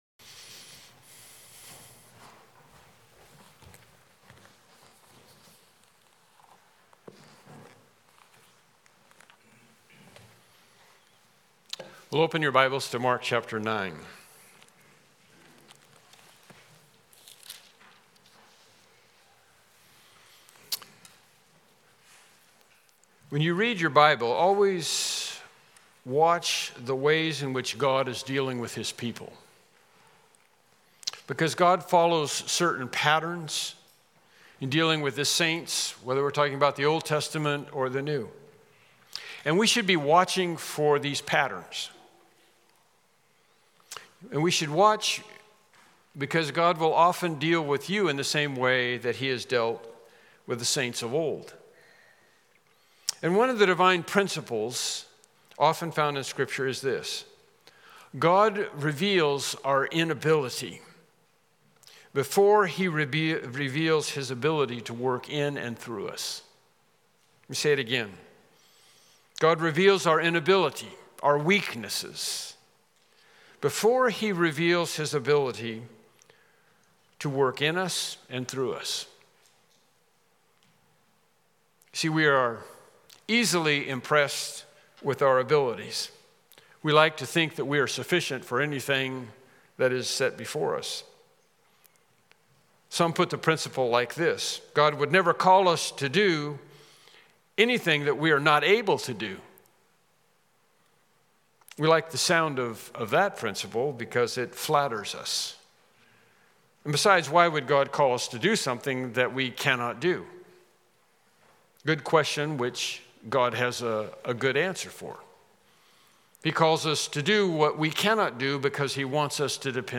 Mark Passage: Mark 9:14-24 Service Type: Morning Worship Service « Lesson 14